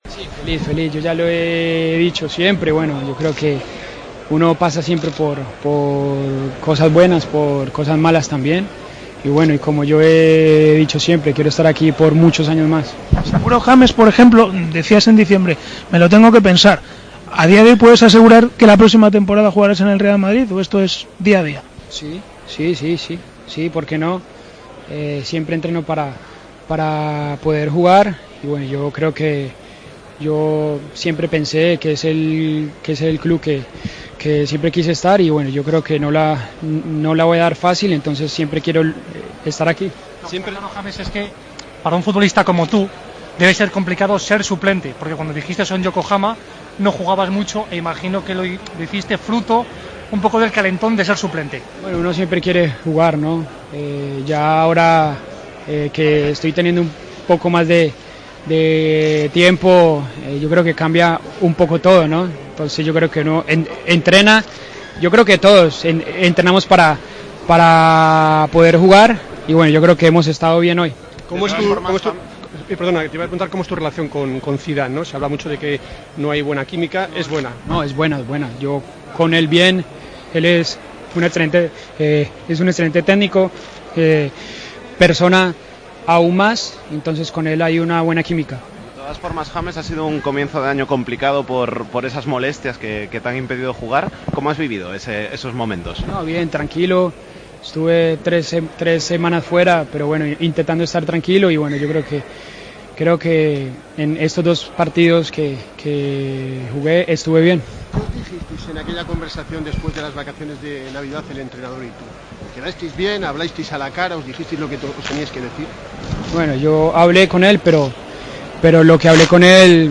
AUDIO: Escuchamos al centroncampista colombiano después de la victoria del Real Madrid ante el Nápoles 3-1, un partido en el que james fue titular.